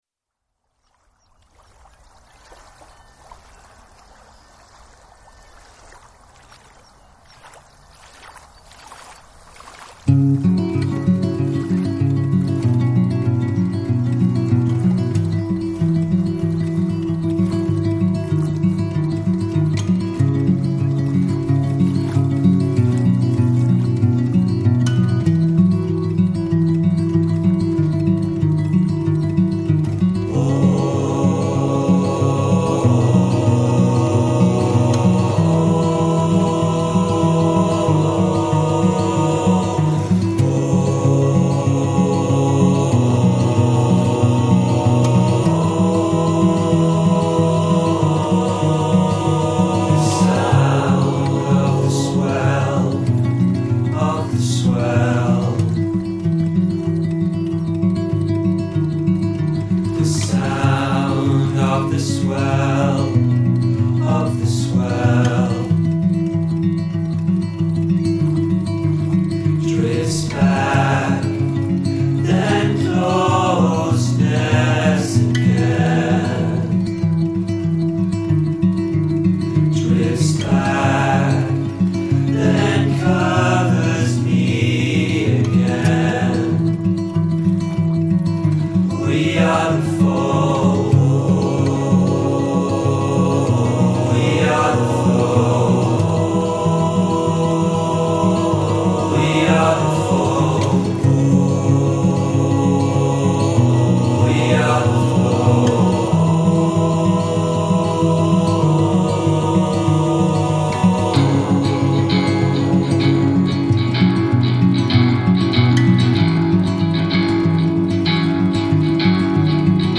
Birdsong in Hamilton reimagined